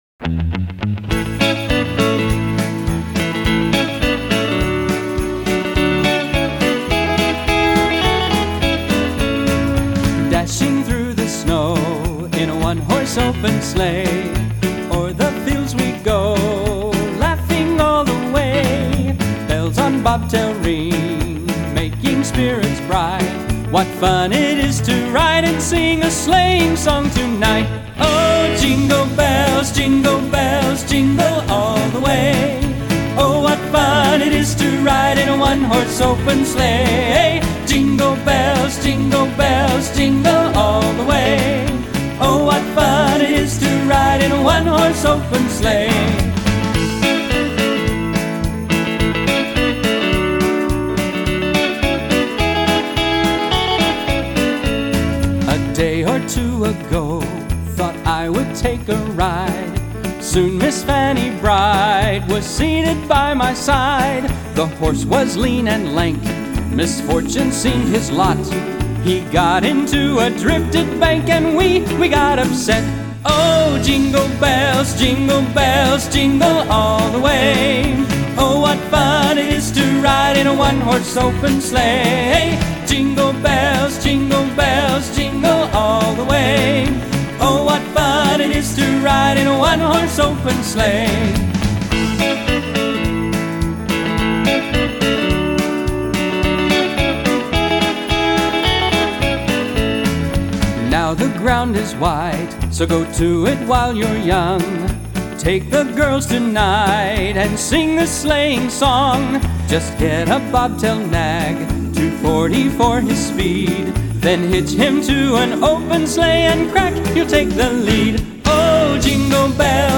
1394 просмотра 709 прослушиваний 65 скачиваний BPM: 106